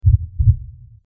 Heartbeat 04
Heartbeat_04.mp3